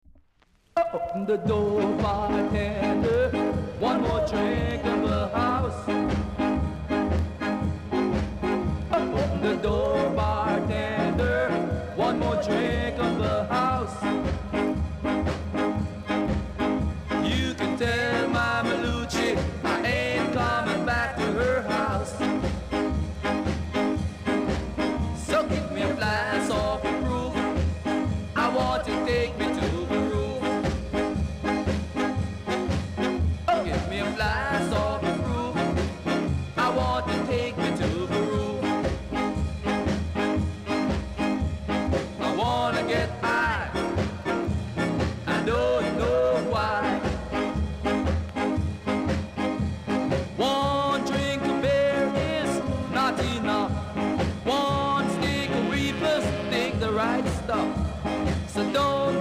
※小さなチリノイズが少しあります。